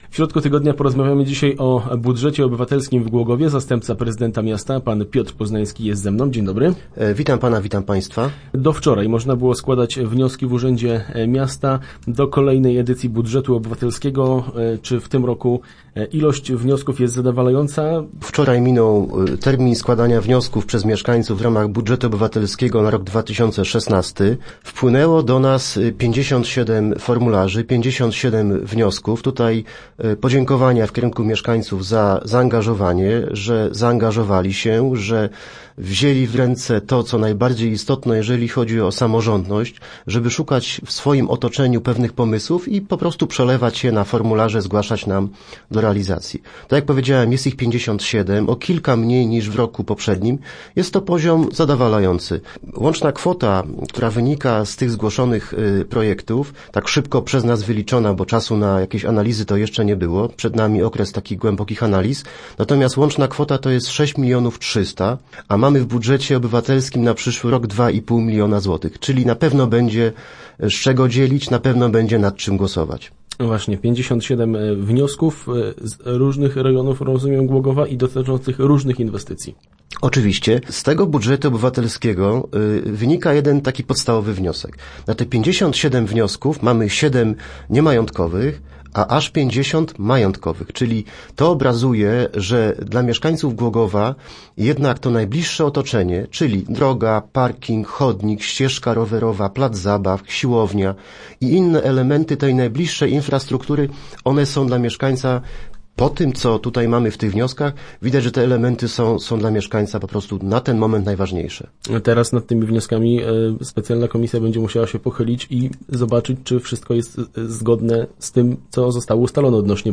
O tym co zawierają i jaka będzie dalsza ich kolej opowiadał w radiowym studiu zastępca prezydenta miasta Piotr Poznański.